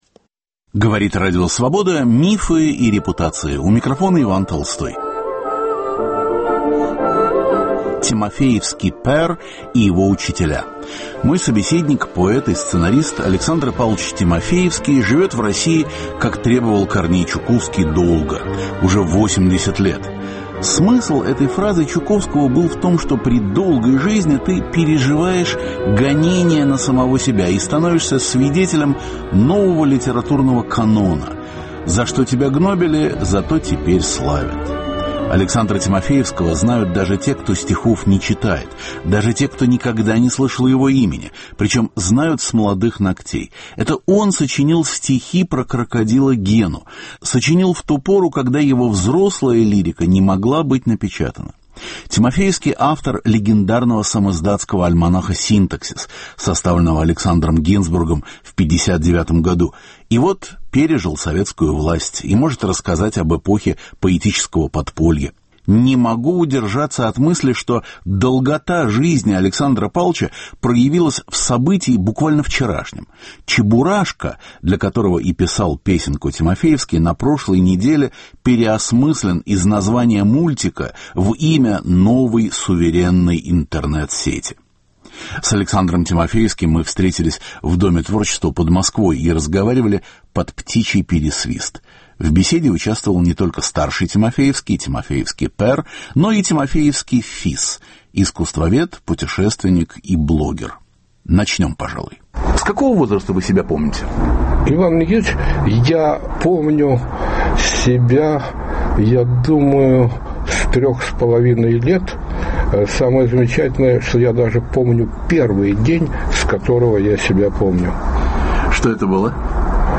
Беседа с 80-летним поэтом и сценаристом Александром Павловичем Тимофеевским, впервые опубликованным только в перестройку. Детство на Украине, блокадные месяцы в Ленинграде, антисталинские стихи. Звучат неопубликованные строки.